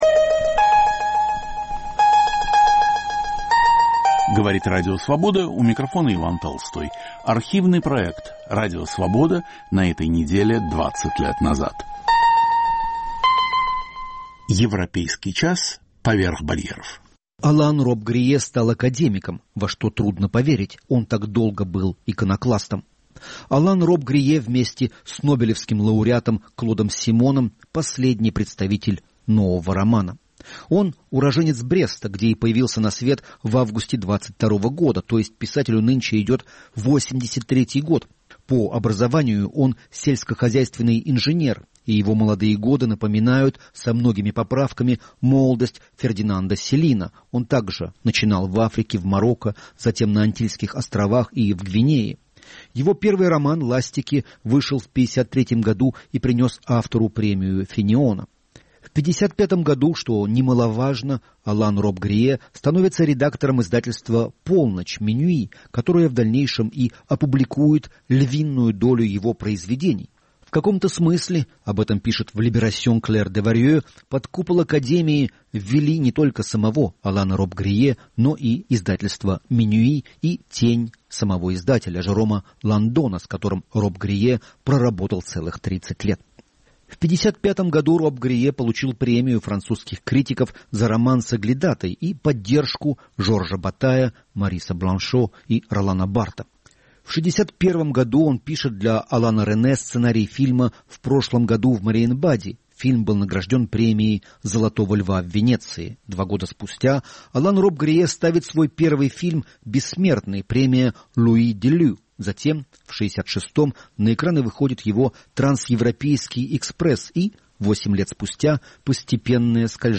Эфир 5 января 2005.